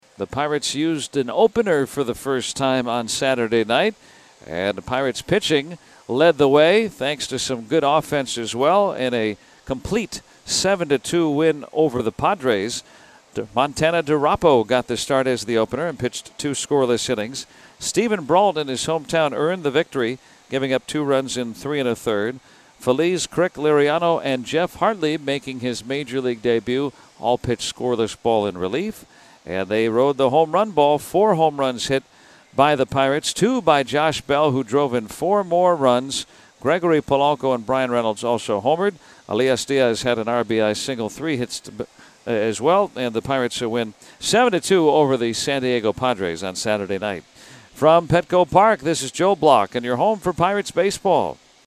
the recap.